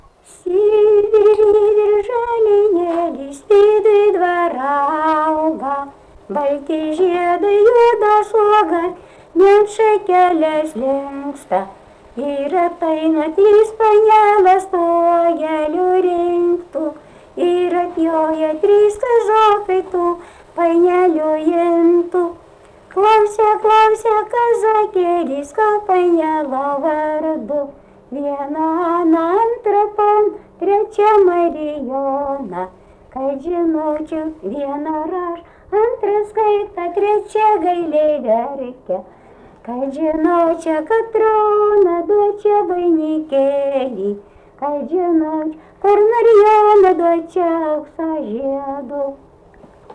Dalykas, tema daina
Erdvinė aprėptis Jonava Bagdoniškis
Atlikimo pubūdis vokalinis